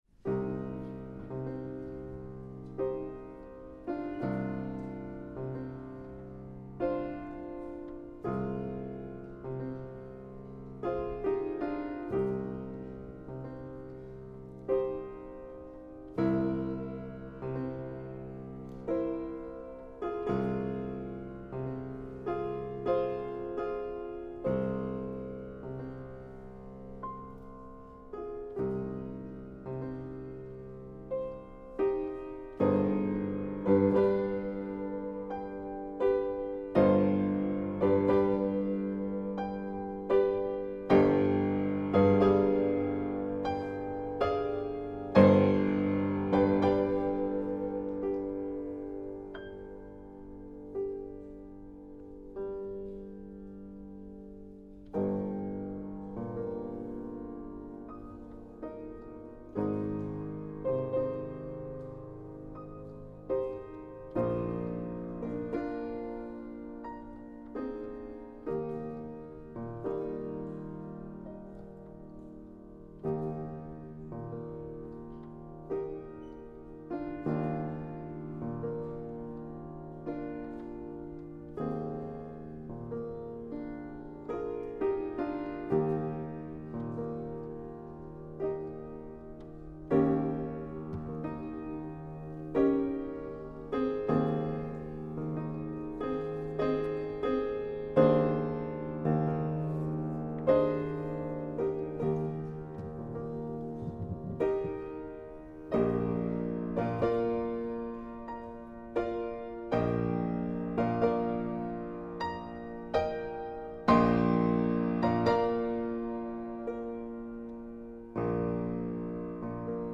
Hammerflügel aus dem Besitz von Carl Maria von Weber
hammerfluegel_brodmann_weber.mp3